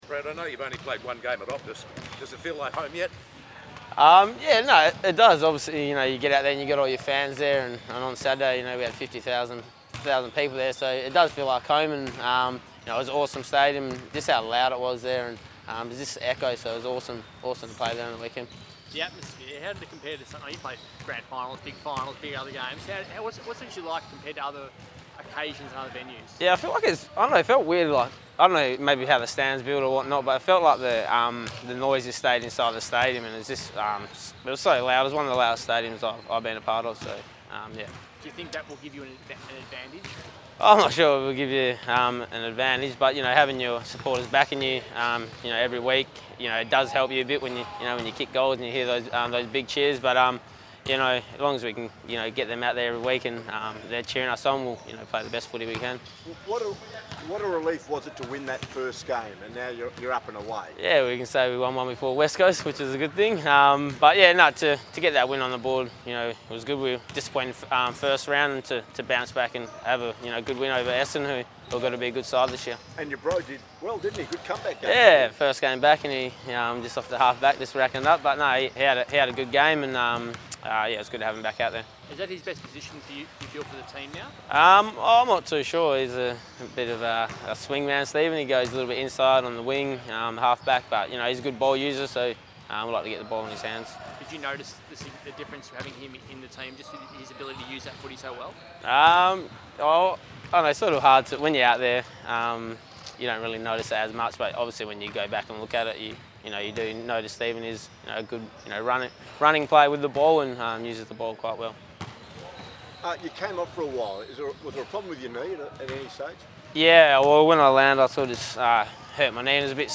Bradley Hill media conference - 2 April
Bradley Hill chats to media after the win against Essendon on Saturday night.